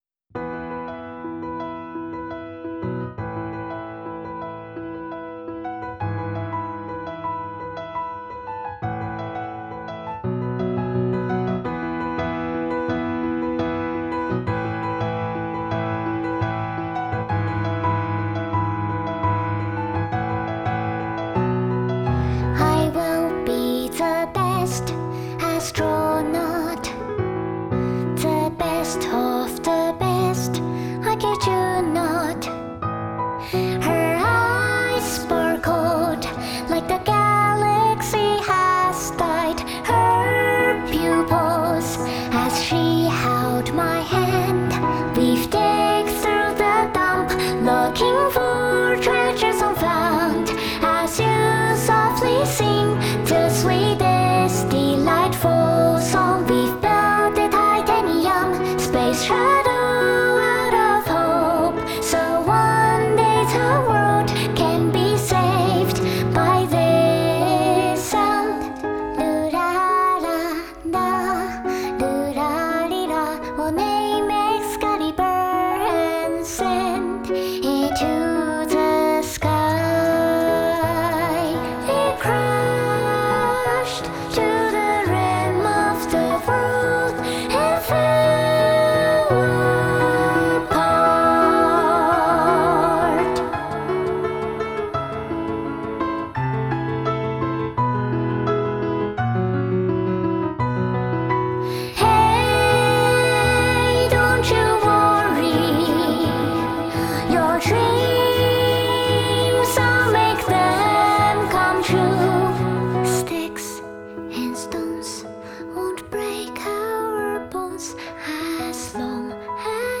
Vocal+Piano Unplugged